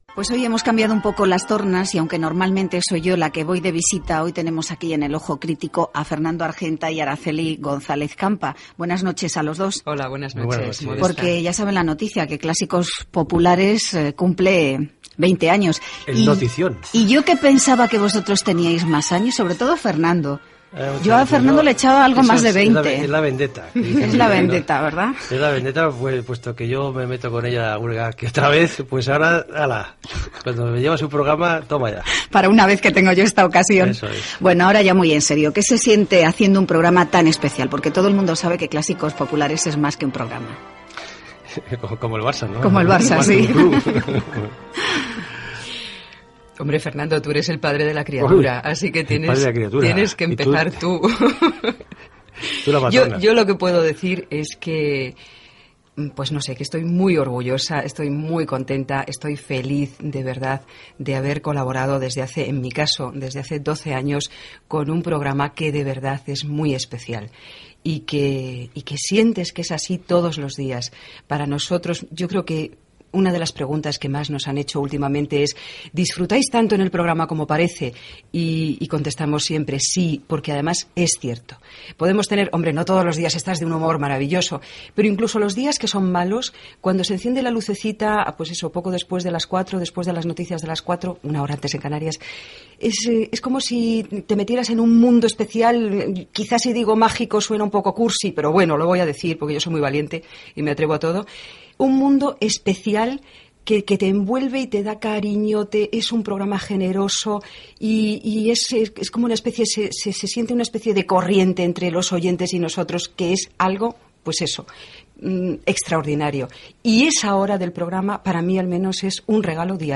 Entrevista
Cultura